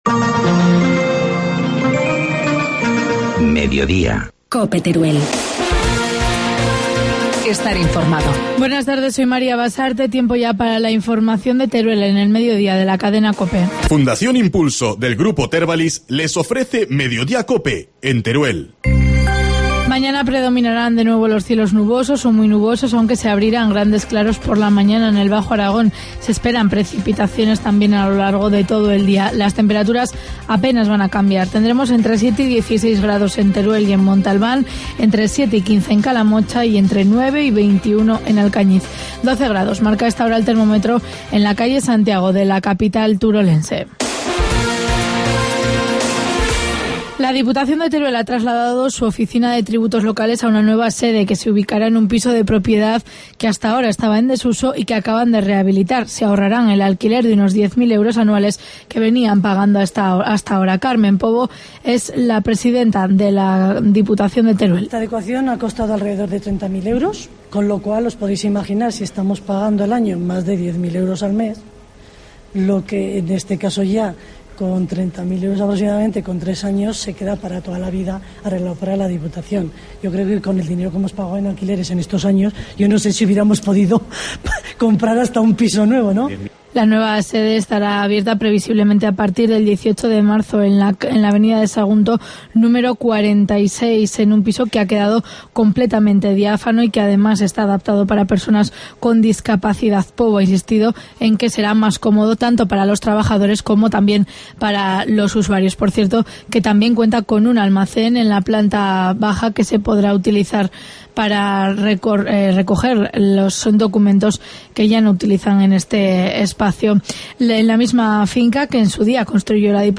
Informativo mediodía, miércoles 6 de marzo